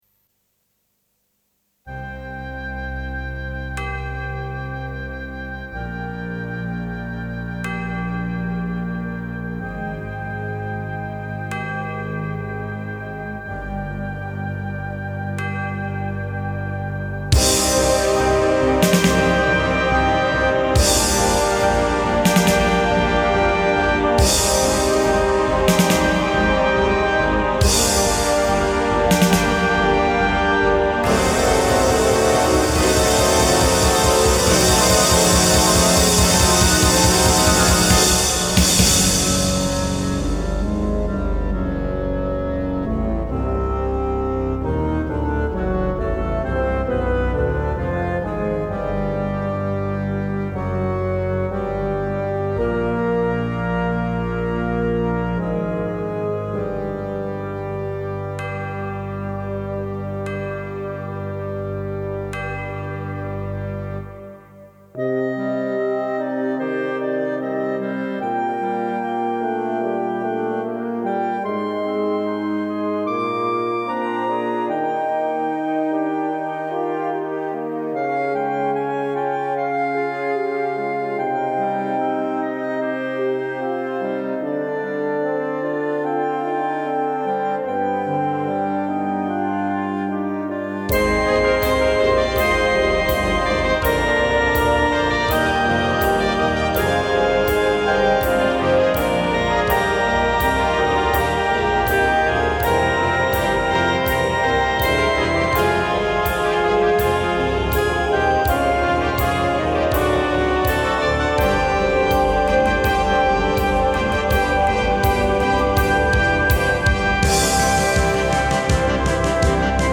For Wind Orchestra